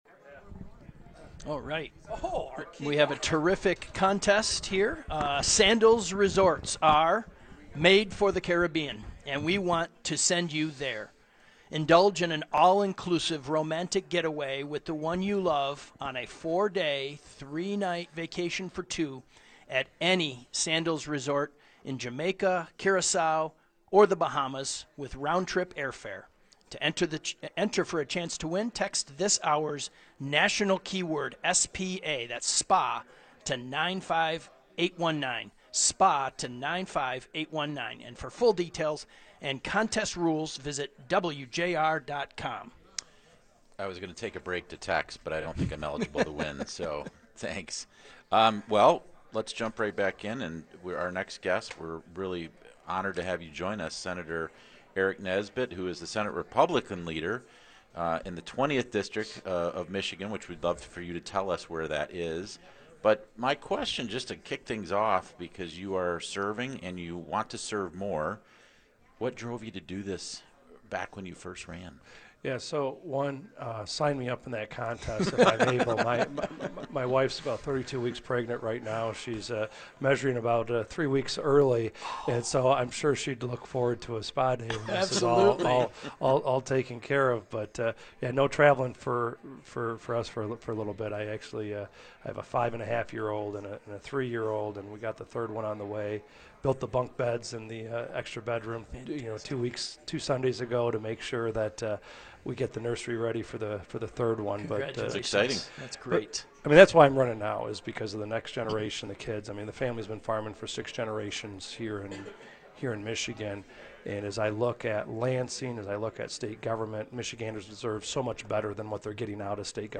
which broadcast live in Lansing prior to Gov.